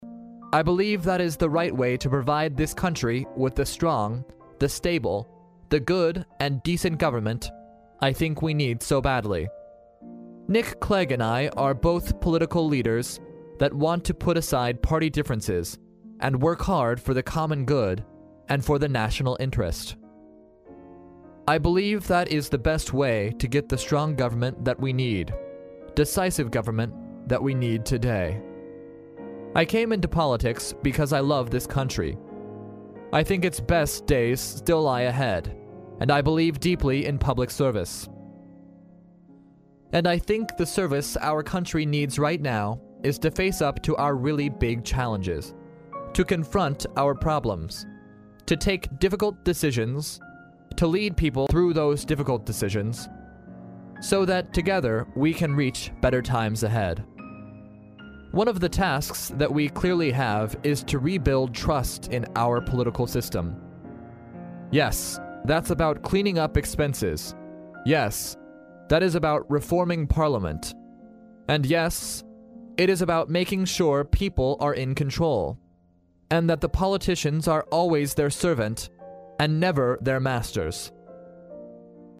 历史英雄名人演讲 第78期:卡梅伦就职演说(2) 听力文件下载—在线英语听力室